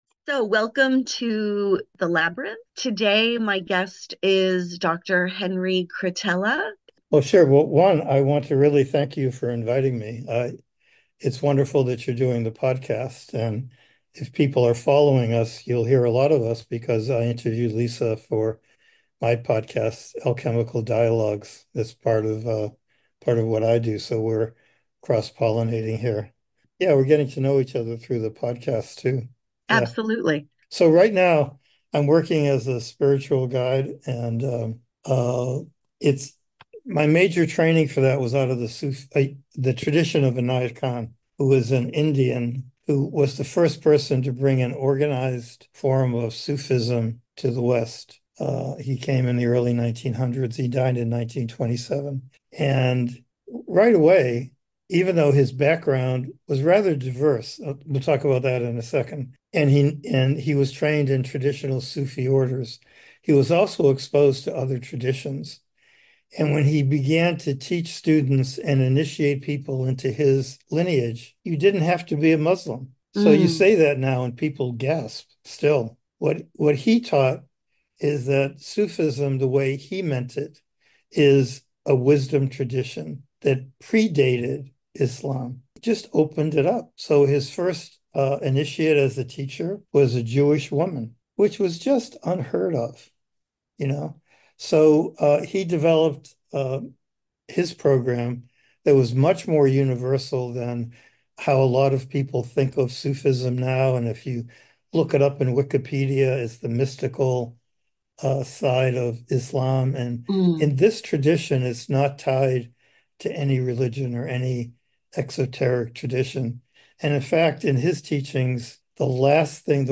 An Exploration of Surrender, Intuition, and Purpose: A Conversation